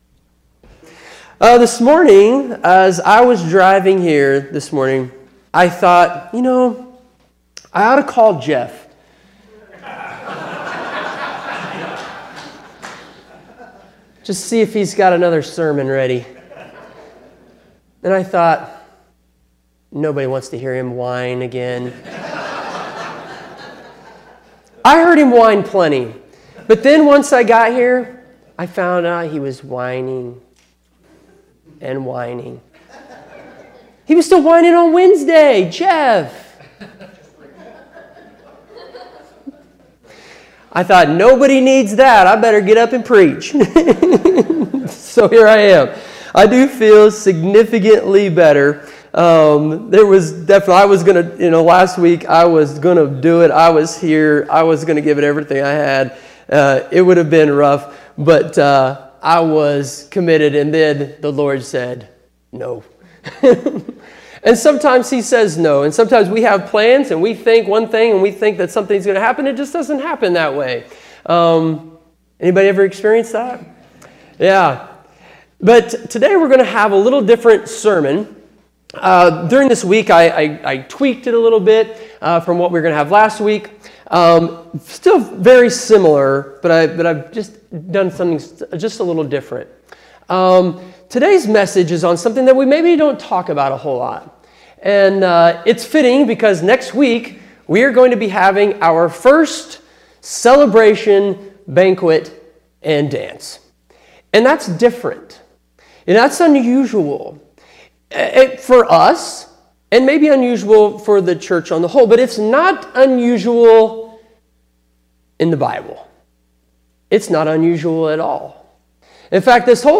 Feb 08, 2025 Celebrating God’s Good Gift Of Marriage MP3 SUBSCRIBE on iTunes(Podcast) Notes Sermon Summary With February comes Valentine's Day, and a reminder of how we are to love one another.